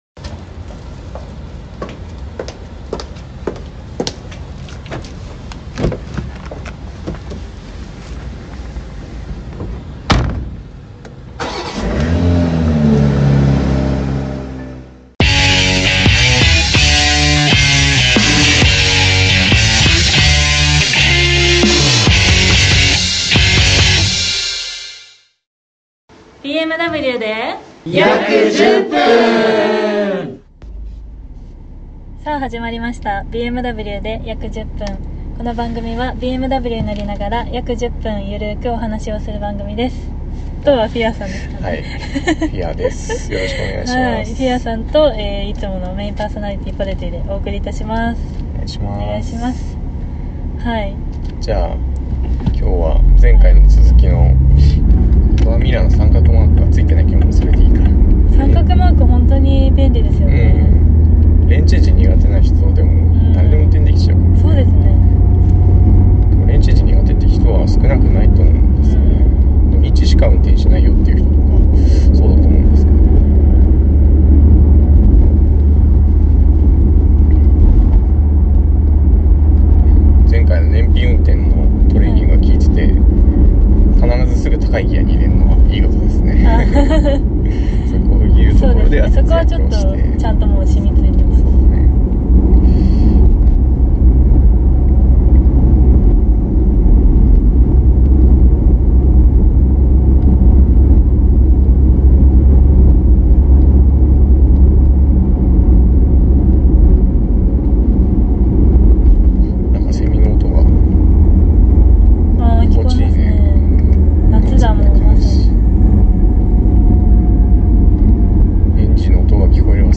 はたしてイカした排気サウンドを生み出せるのでしょうか。